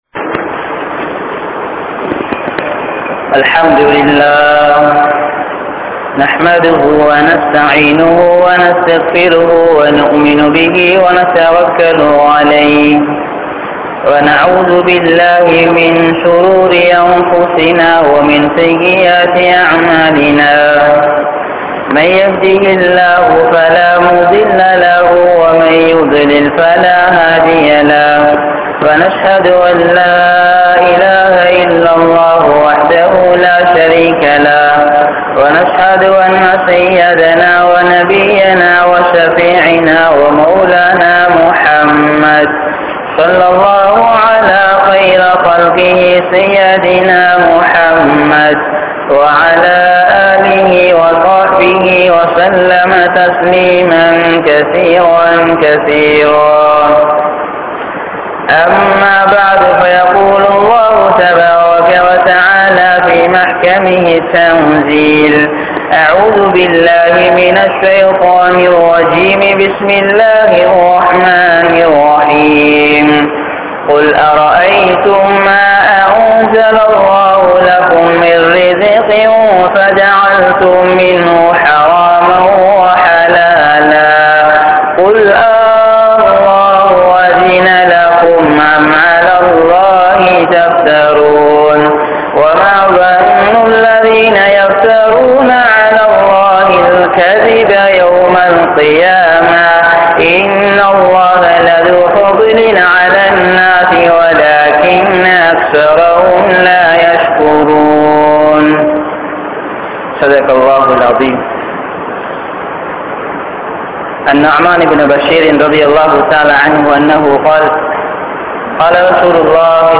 Halal & Haram | Audio Bayans | All Ceylon Muslim Youth Community | Addalaichenai